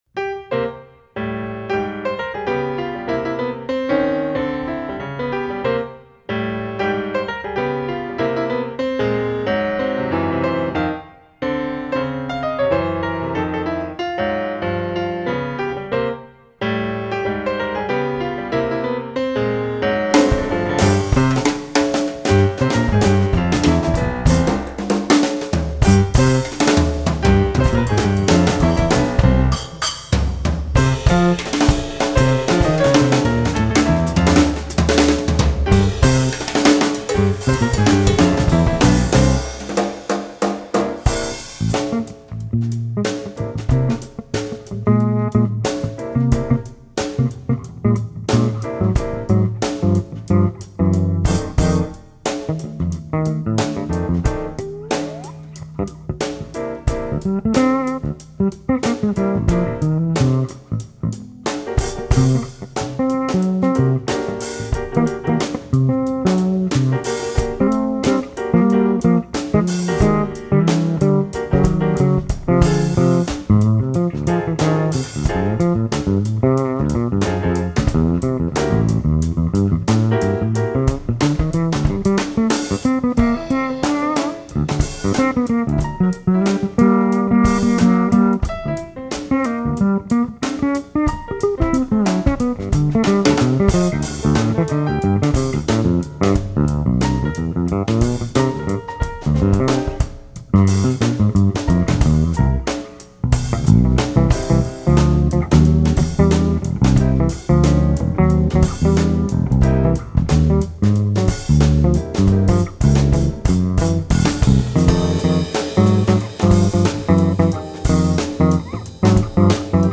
piano
fretless electric bass
drums/percussion